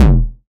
9KICK.wav